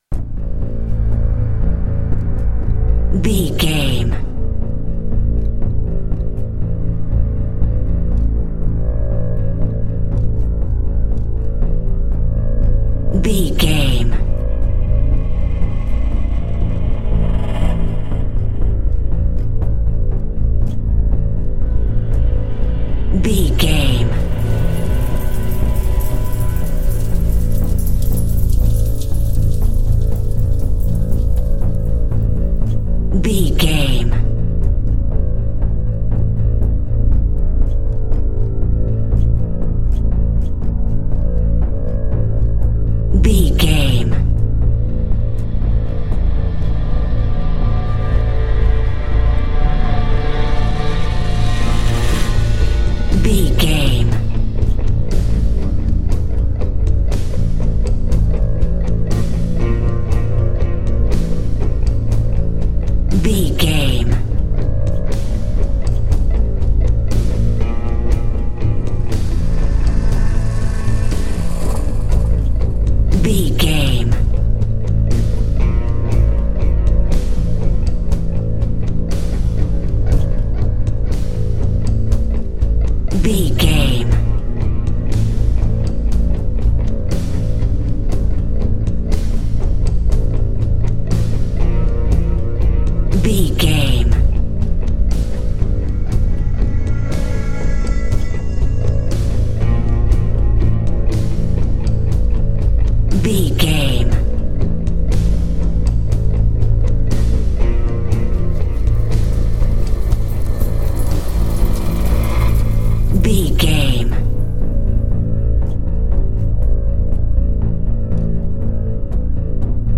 Aeolian/Minor
Slow
piano
synthesiser
electric guitar
ominous
dark
suspense
haunting
tense
creepy